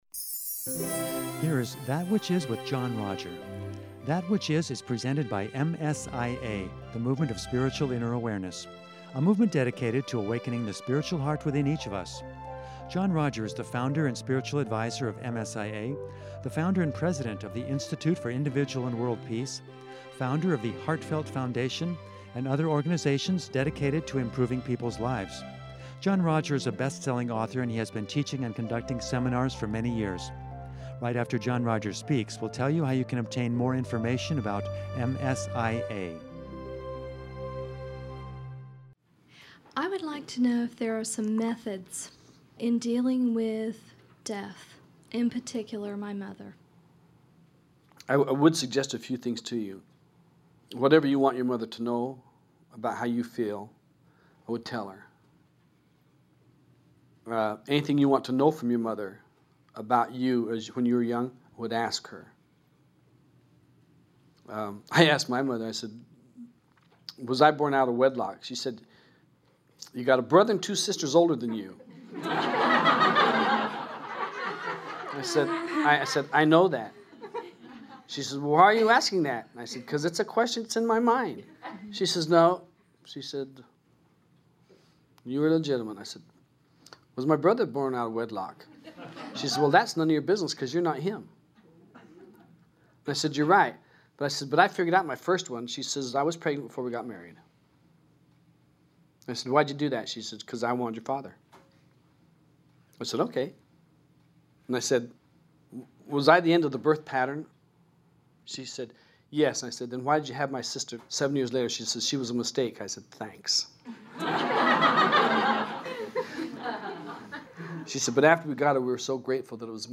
This seminar
during a Spiritual Warrior Retreat question-and-answer session